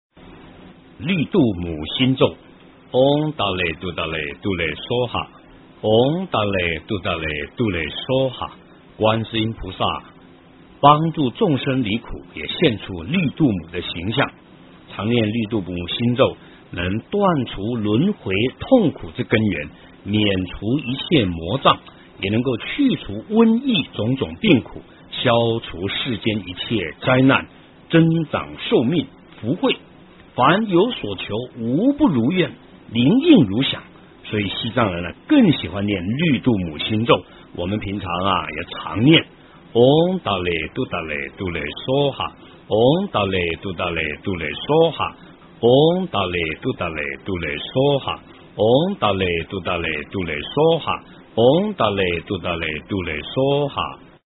诵经
佛音 诵经 佛教音乐 返回列表 上一篇： 六字弥陀 下一篇： 南无观世音菩萨 相关文章 貧僧有話9說：我怎样走上国际的道路--释星云 貧僧有話9說：我怎样走上国际的道路--释星云...